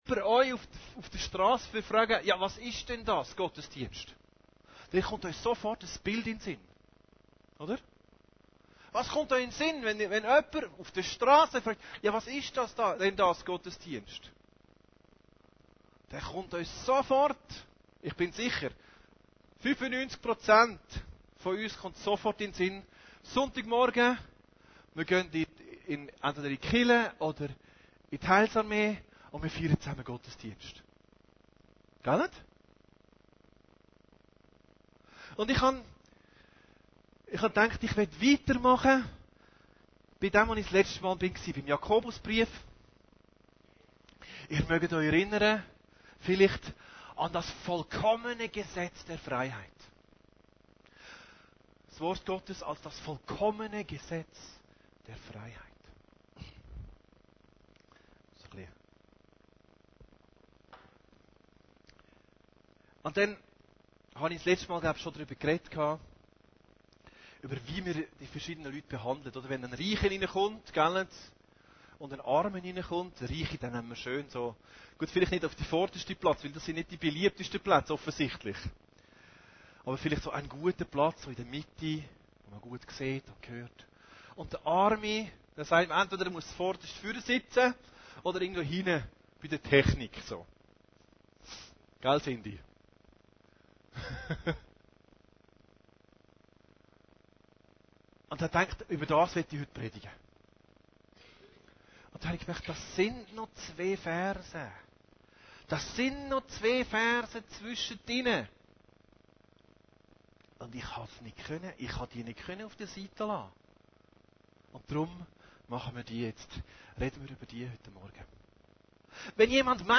Allgemeine Predigten Date